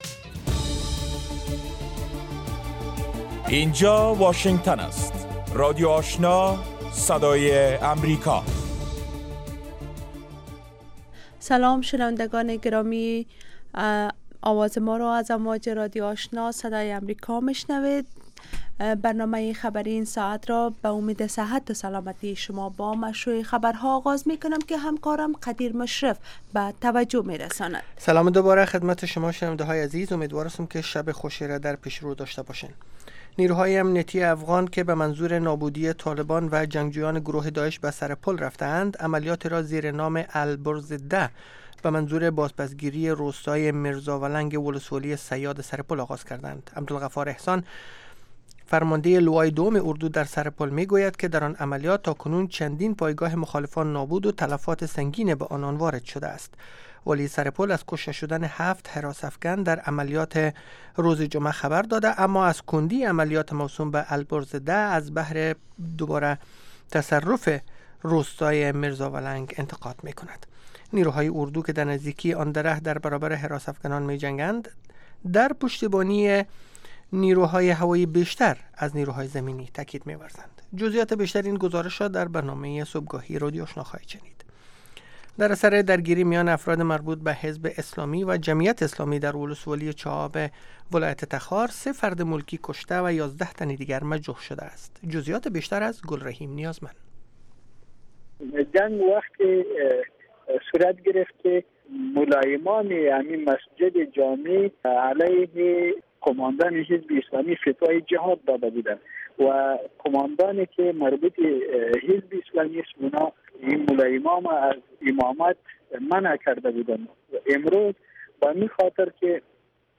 دومین برنامه خبری شب